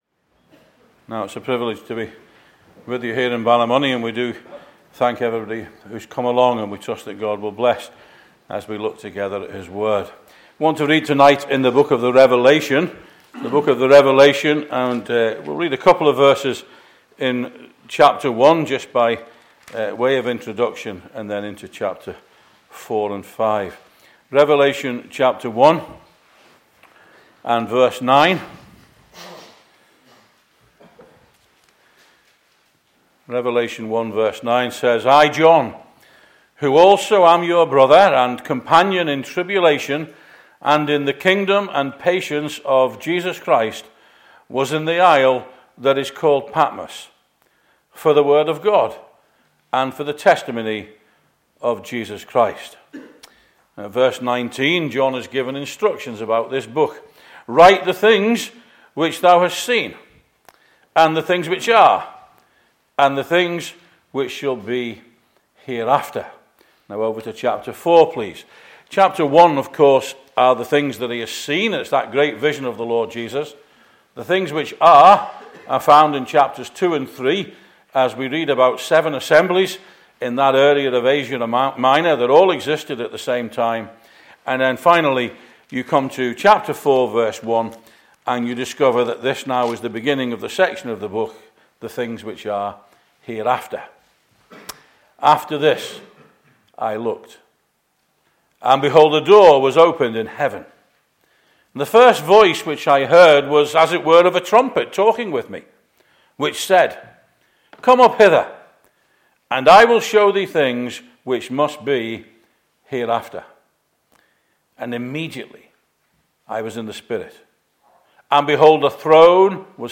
Meeting Type: Ministry